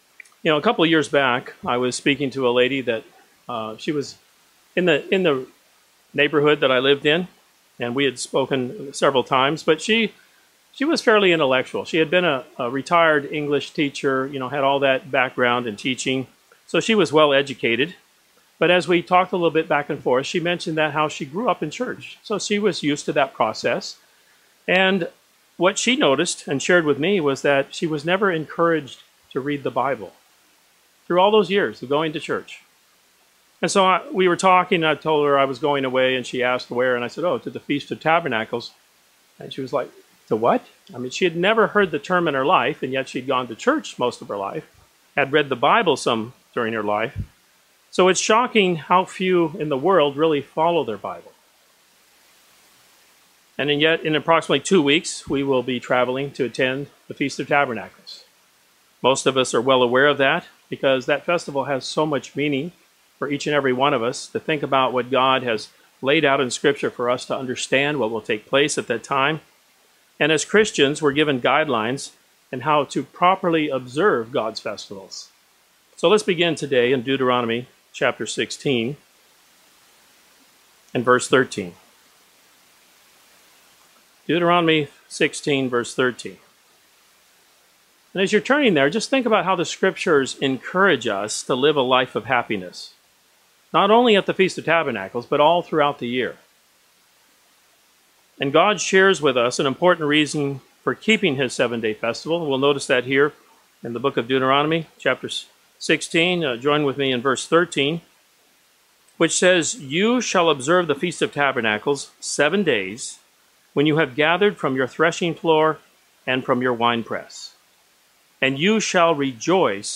This sermon shares three simple truths that prepare us for greater joy.